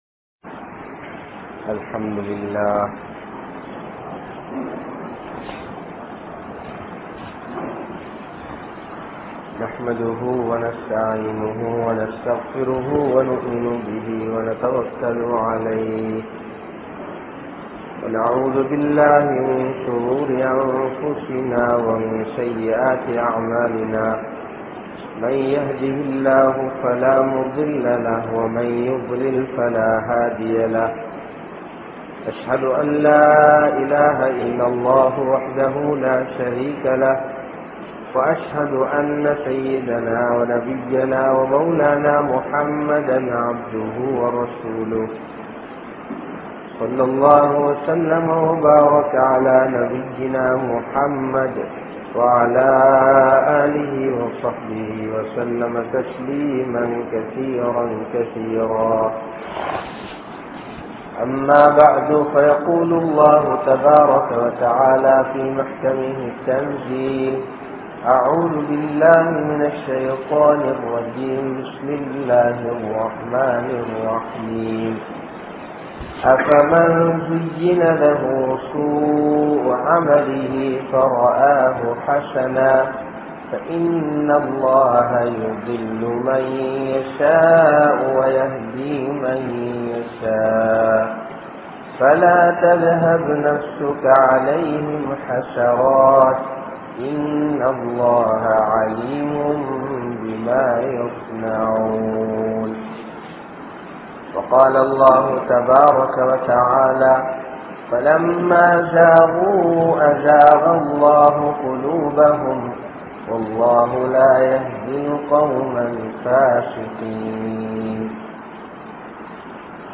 Niumaththukkalai Parikkum Paavangal (நிஃமத்துக்களை பரிக்கும் பாவங்கள்) | Audio Bayans | All Ceylon Muslim Youth Community | Addalaichenai
Pallimulla Masjidh